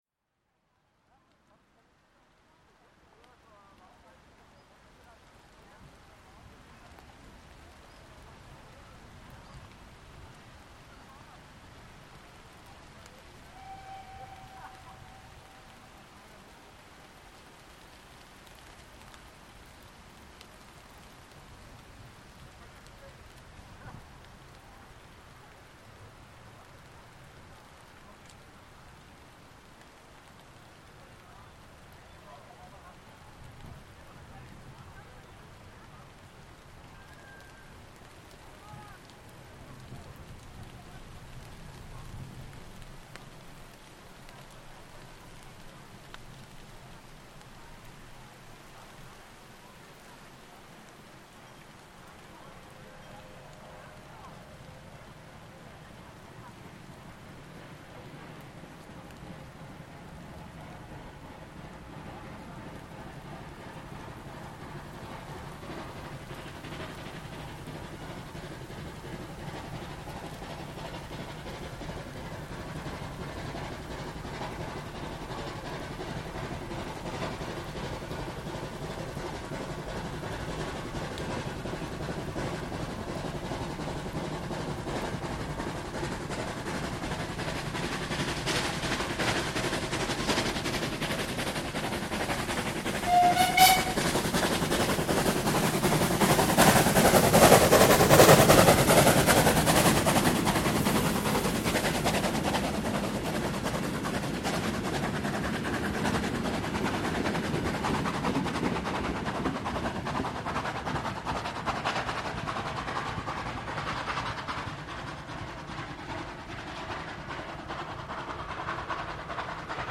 Die Qualiät der dabei entstandenen Soundaufnahmen war überraschend so gut, dass man sie als die besten der 886 überhaupt im ganzen Archiv bezeichnen kann:
Keine Autos zu hören! Nur einige sich laut unterhaltende Radfahrer-Gruppen.
98 886  mit Zug Ostheim→Fladungen, vor der Einfahrt vom Bahnhof Fladungen aufgenommen im strömenden Regen, um 14:02h am 03.08.2025. wieder in ziemlich schnellen Tempo mit einer phantastischen Fahrweise und Lautstärke - ganz ohne "Bestellung".